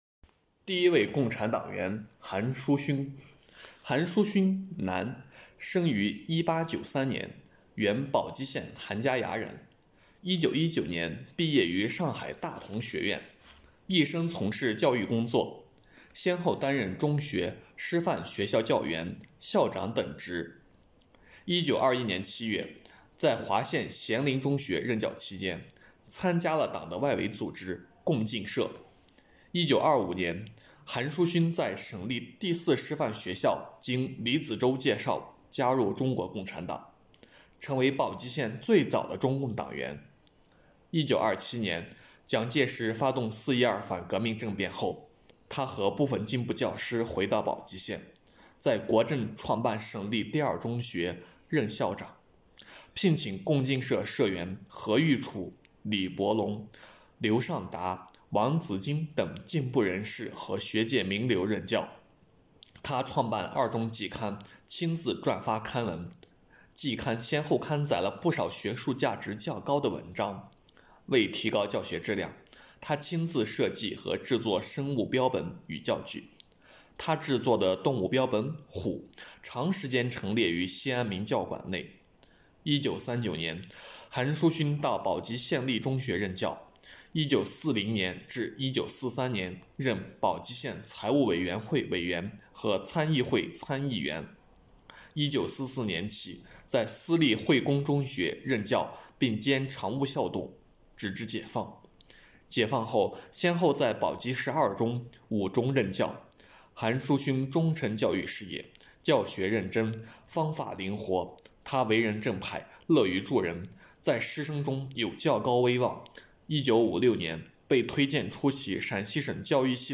【红色档案诵读展播】陈仓区第一位共产党员韩叔勋